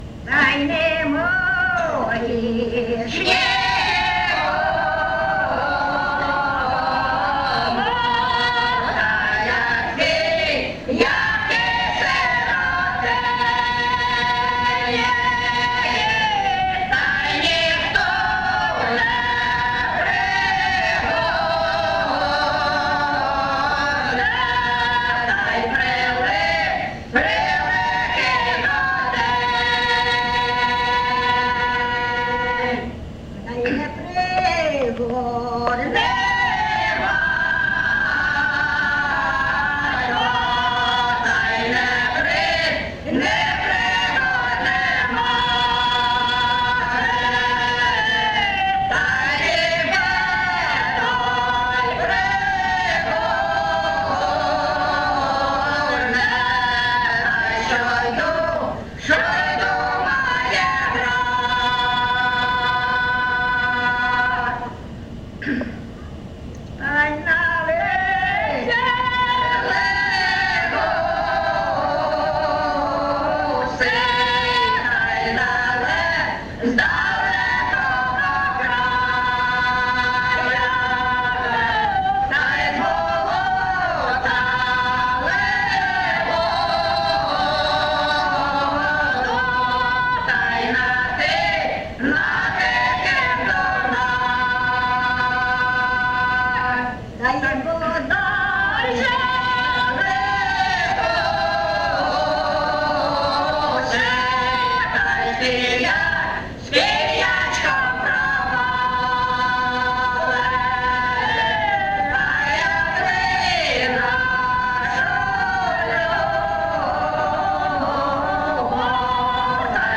ЖанрПісні з особистого та родинного життя, Сирітські
Місце записус. Очеретове, Валківський район, Харківська обл., Україна, Слобожанщина